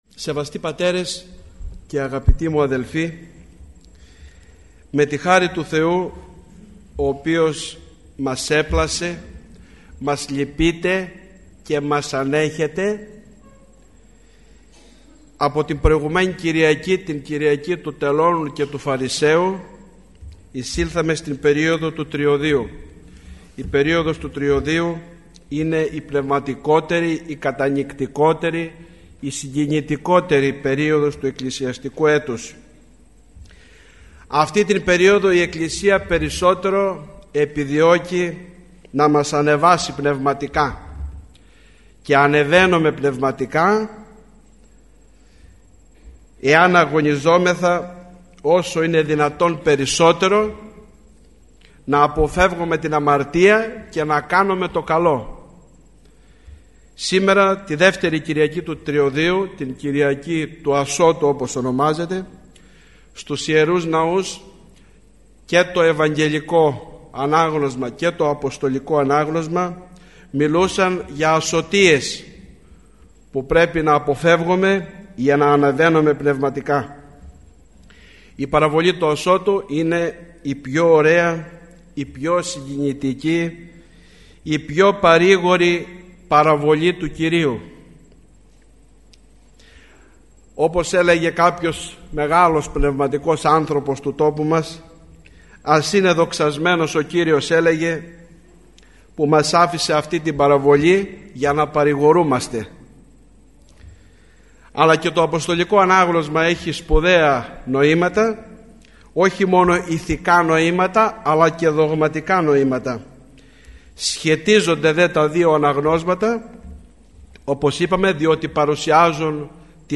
Την ομιλία “επισφραγίζει” ο Μητροπολίτης, ο Αιτωλίας και Ακαρνανίας Κοσμάς. Η ομιλία αυτή “πραγματοποιήθηκε” στην αίθουσα της Χριστιανικής Ενώσεως Αγρινίου.